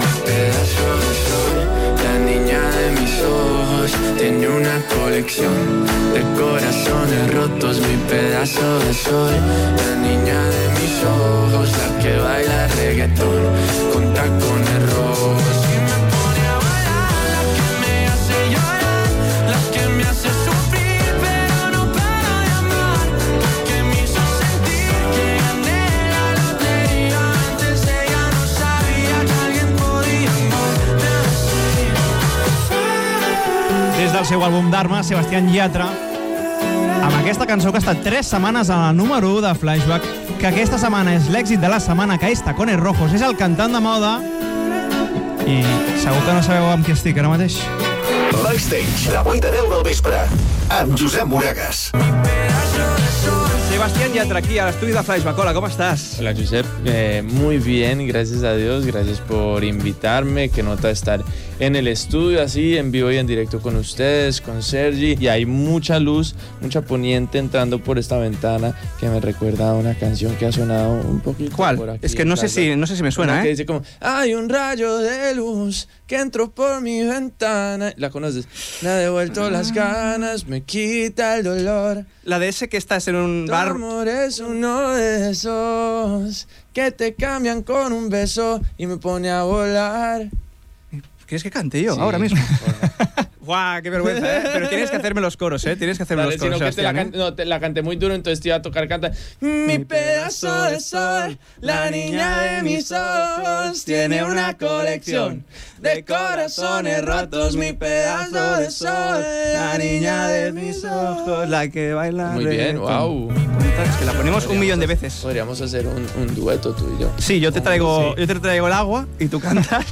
03d163ea23ef0e873a439f58cb1a00c06deec99d.mp3 Títol Ràdio Flaixbac Emissora Ràdio Flaixbac Cadena Flaix Titularitat Privada nacional Nom programa Bacstage Descripció Tema musical, indicatiu del programa, entrevista al cantant colombià Sebastián Yatra que acaba de publicar el disc "Dharma".